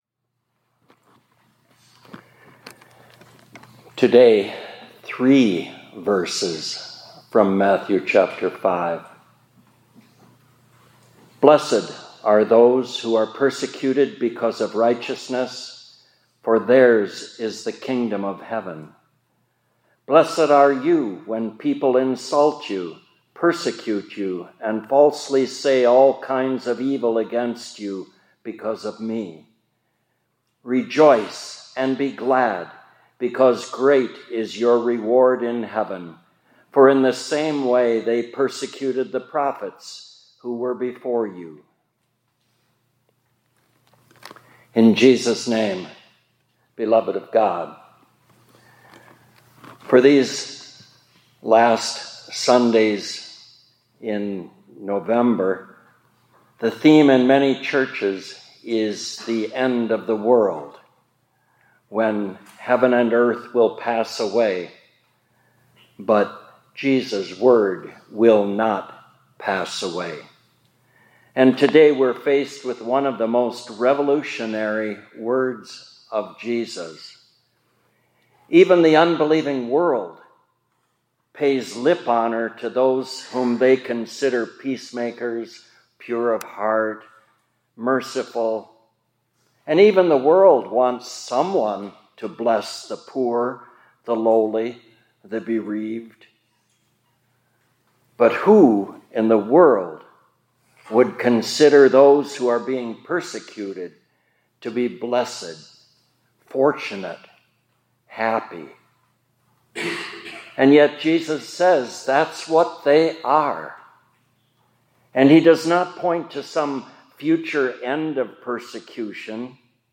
2024-11-19 ILC Chapel — Blessed are the Persecuted